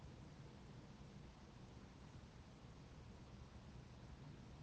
The Kurdish and Gorani Dialect Database
fieldwork 2021